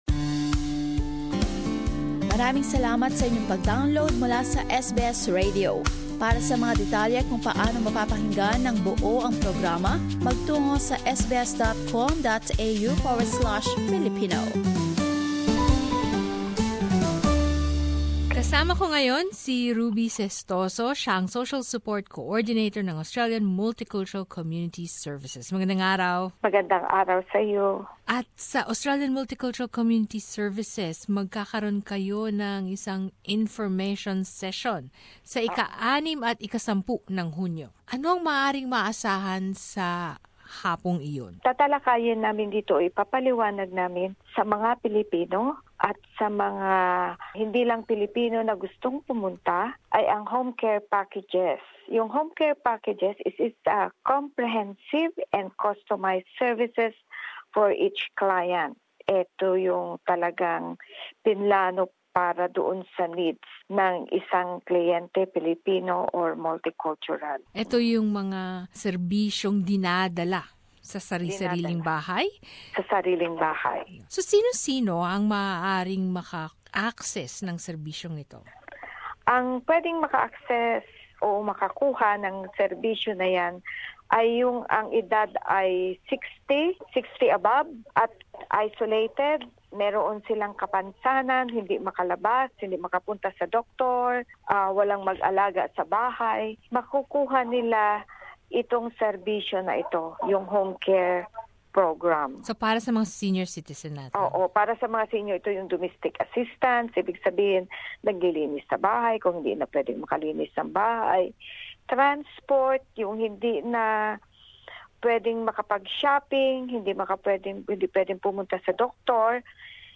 panayam